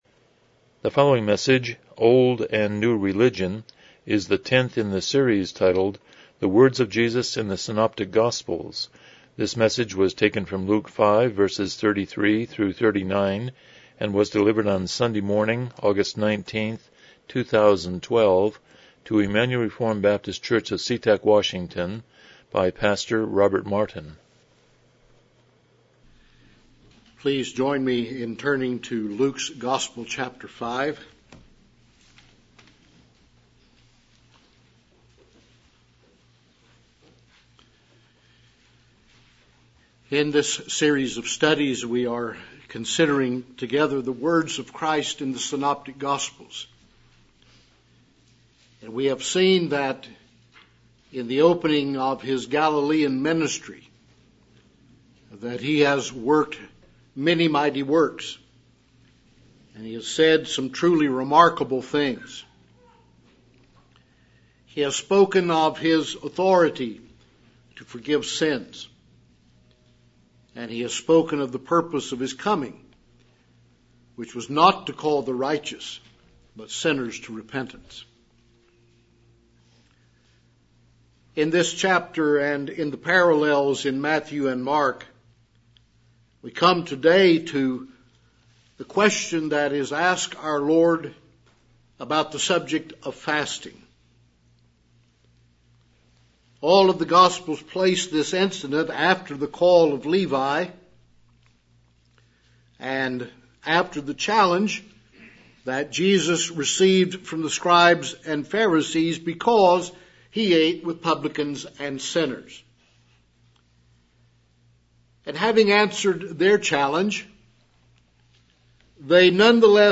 Passage: Luke 5:33-39 Service Type: Morning Worship